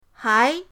hai2.mp3